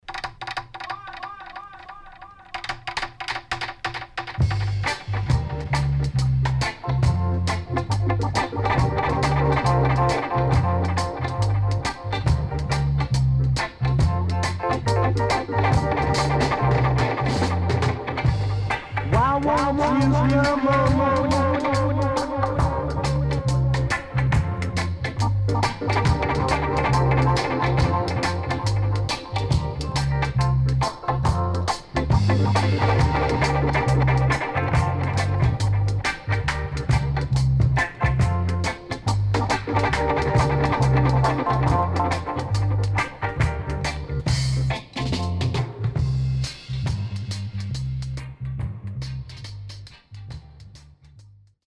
HOME > REISSUE [REGGAE / ROOTS]
Great Funky Inst